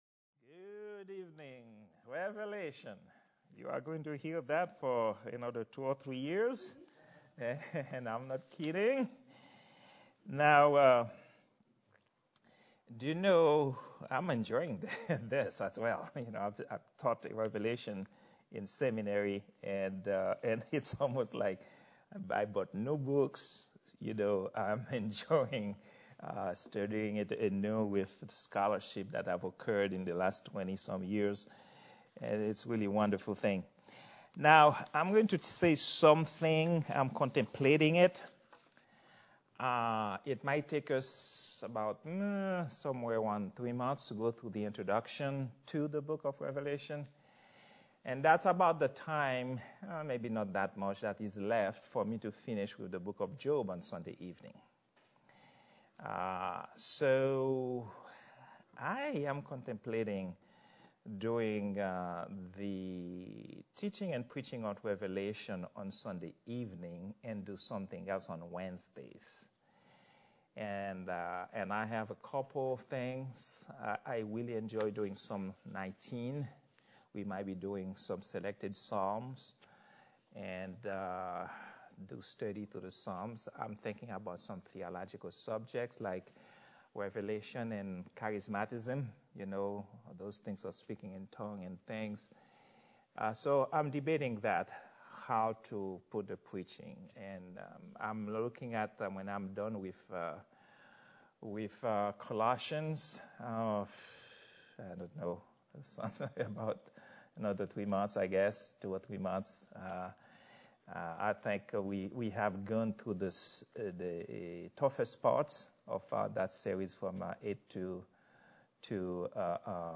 Prayer_Meeting_04_13_2022.mp3